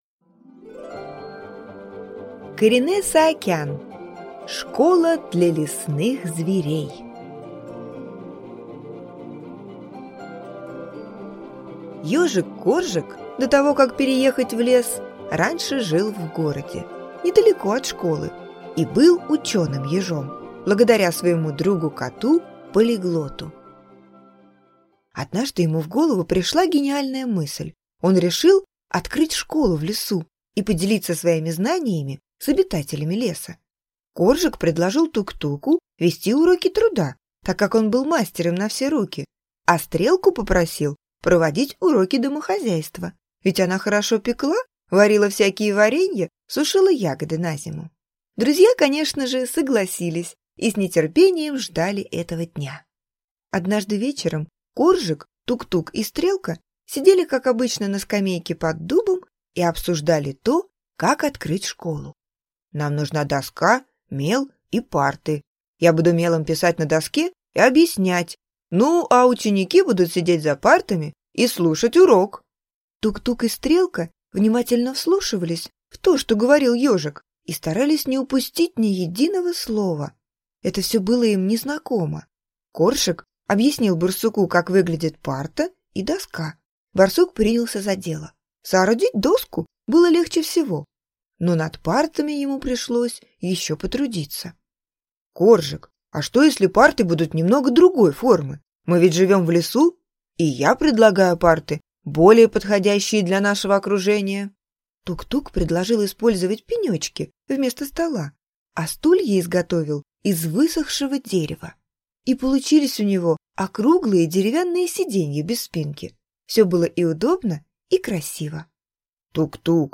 Аудиокнига Школа для лесных зверей | Библиотека аудиокниг